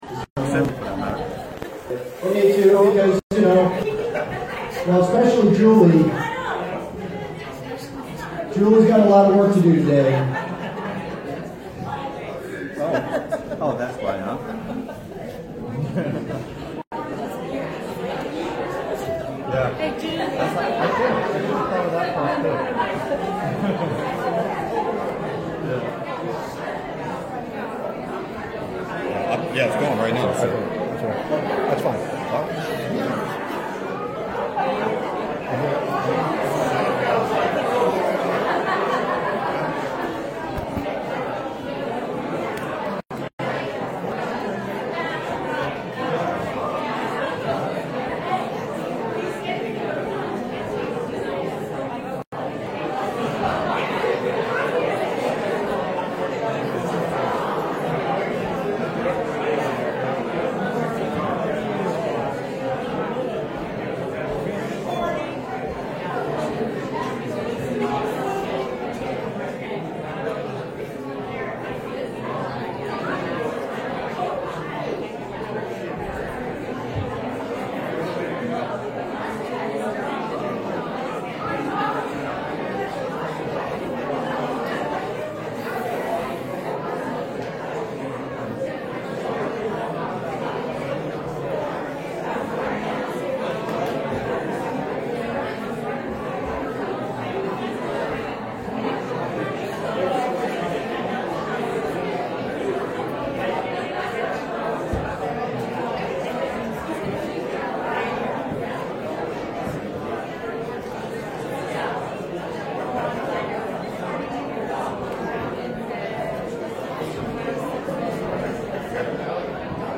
Passage: Isaiah 7:14 Service Type: Sunday Morning